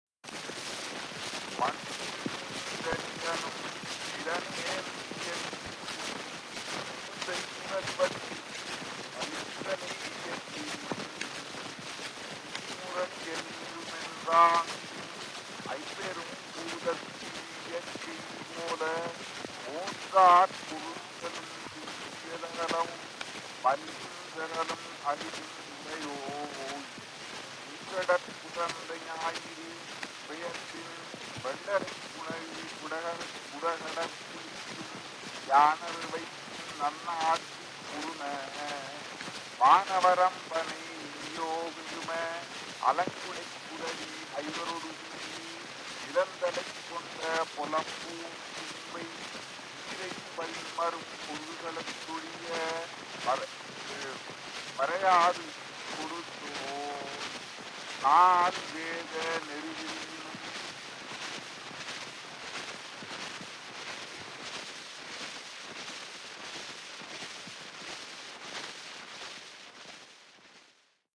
chiefly recitations of classical Tamil poetry
Historical sound recordings